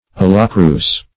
Search Result for " allochroous" : The Collaborative International Dictionary of English v.0.48: Allochroous \Al*loch"ro*ous\, a. [Gr.